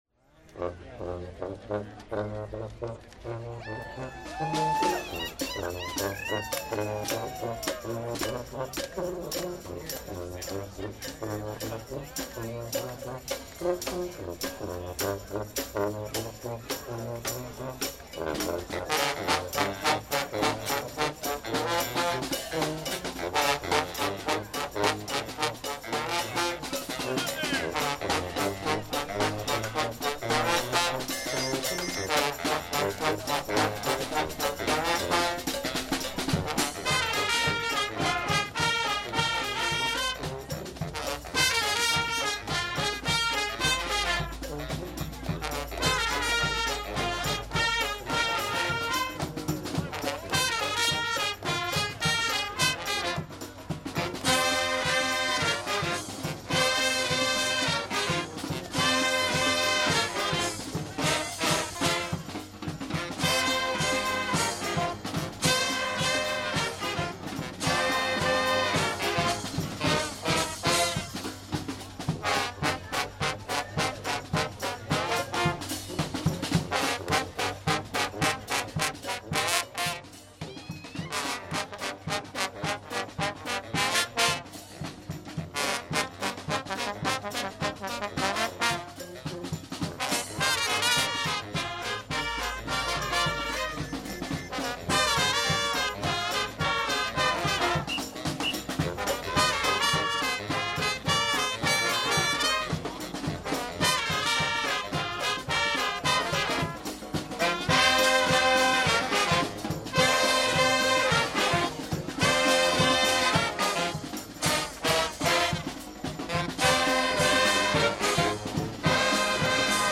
Bx2 Trumpet melody Cx2 tutti section Ax4 Trombones Bx2 Trumpet melody Cx2 tutti section Dx2 Descending line played by bones and reeds, E joined by trumpets for bridge ? Solos: Bone with trumpets behind,Sax with bones behind, Trumpet with saxes behind
key Fm